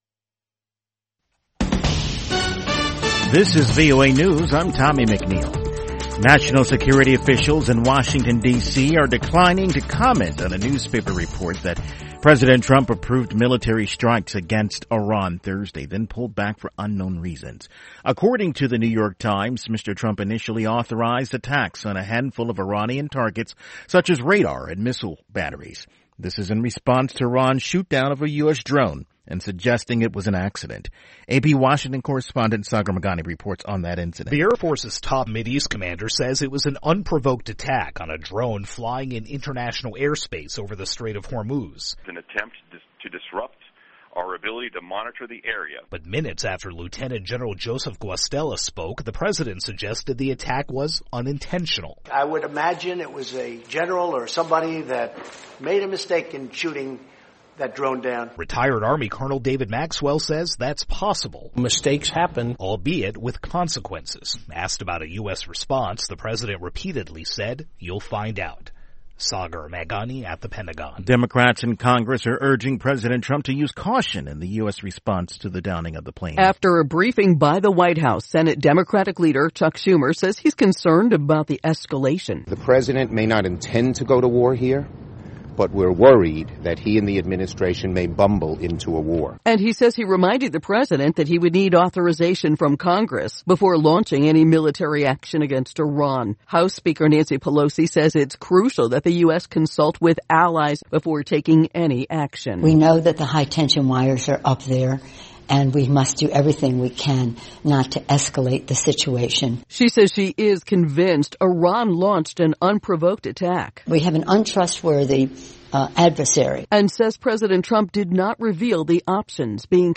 Each day, Daybreak Africa looks at the latest developments on the continent, starting with headline news and providing in-depth interviews, reports from VOA correspondents, sports news and a selection of listeners' letters.